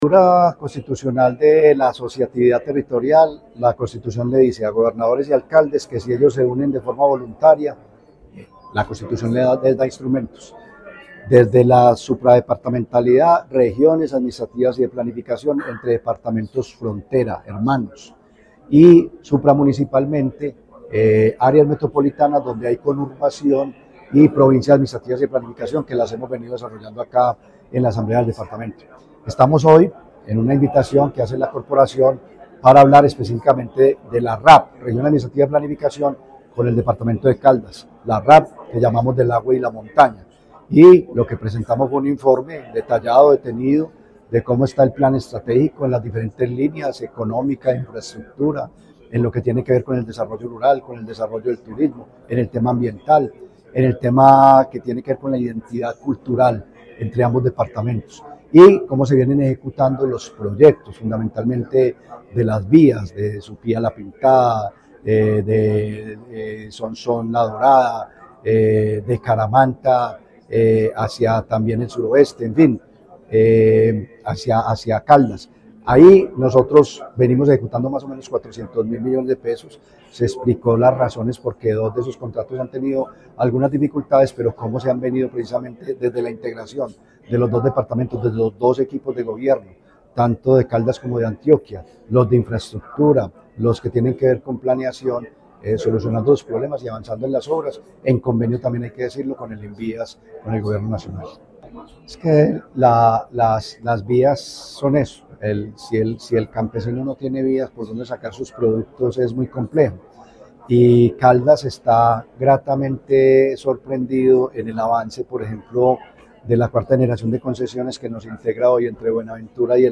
Ante la Asamblea de Antioquia, el secretario de Planeación de Caldas y gerente (e) de la RAP del Agua y la Montaña, Carlos Anderson García, y el director del Departamento Administrativo de Planeación de Antioquia (DAP), Eugenio Prieto Soto, dieron a conocer los avances en la implementación de la Región Administrativa de Planificación (RAP), iniciativa que trabaja por fortalecer la integración y el desarrollo conjunto entre estos dos departamentos hermanos.
Director del Departamento Administrativo de Planeación de Antioquia (DAP), Eugenio Prieto Soto.